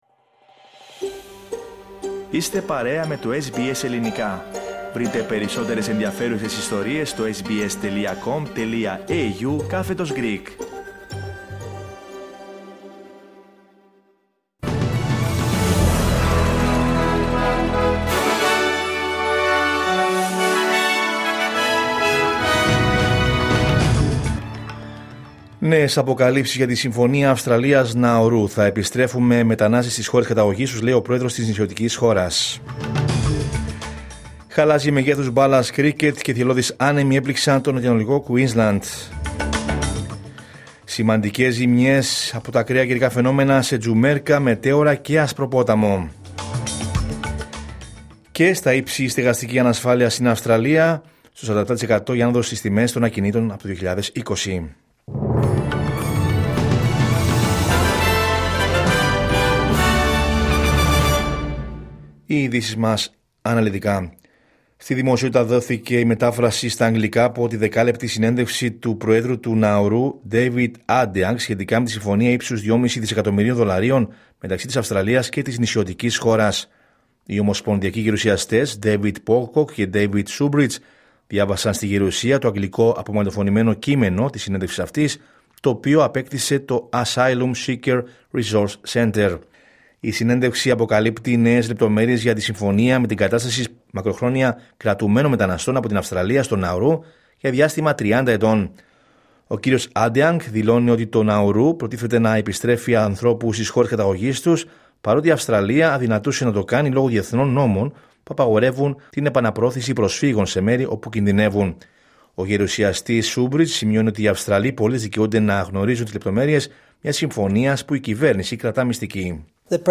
Ειδήσεις από την Αυστραλία, την Ελλάδα, την Κύπρο και τον κόσμο στο Δελτίο Ειδήσεων της Τρίτης 25 Νοεμβρίου 2025.